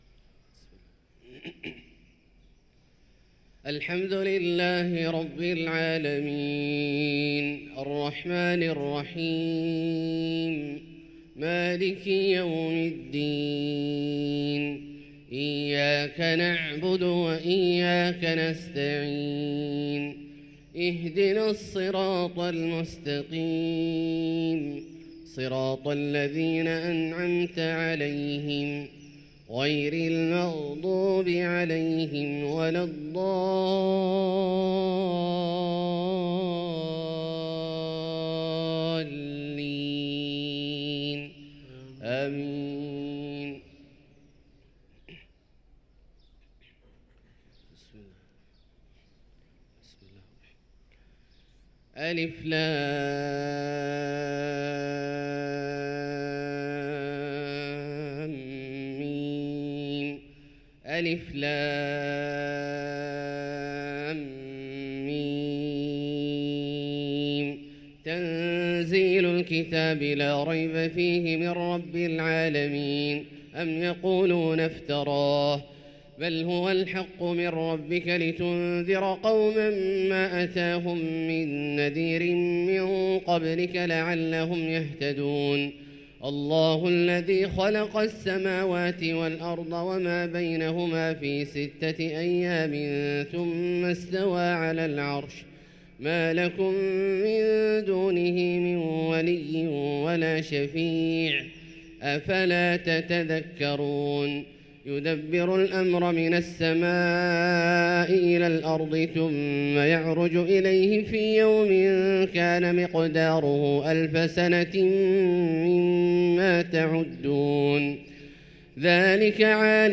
صلاة الفجر للقارئ عبدالله الجهني 24 جمادي الأول 1445 هـ
تِلَاوَات الْحَرَمَيْن .